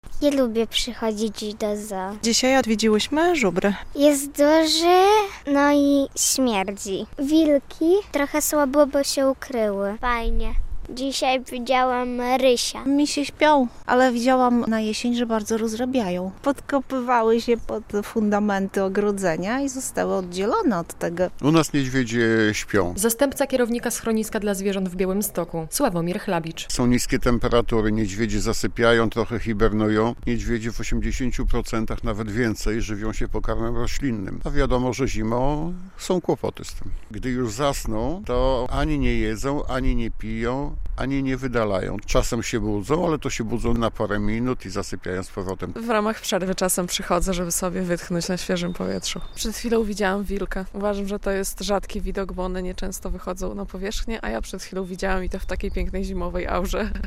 Zimowe spacery do Akcentu ZOO, gdy misie śpią - relacja